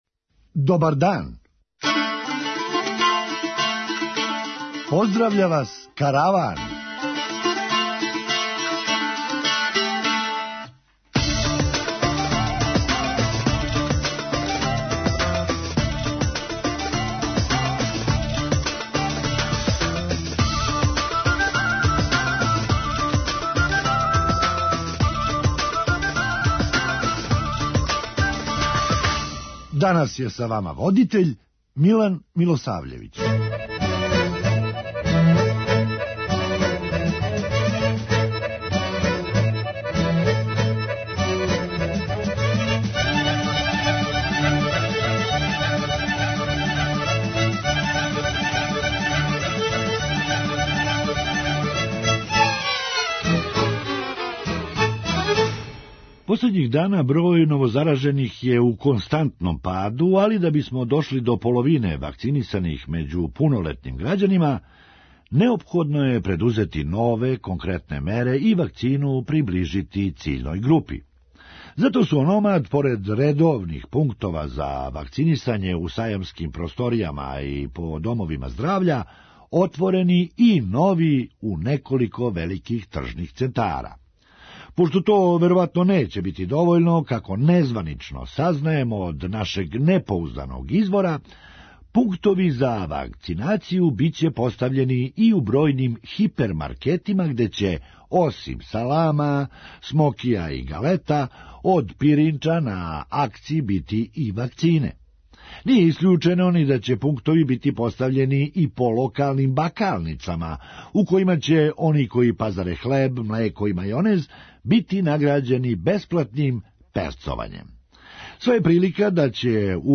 Хумористичка емисија
А, како је кренуло, ускоро ће сами себе оцењивати и уписивати оцене у дневник. преузми : 9.57 MB Караван Autor: Забавна редакција Радио Бeограда 1 Караван се креће ка својој дестинацији већ више од 50 година, увек добро натоварен актуелним хумором и изворним народним песмама.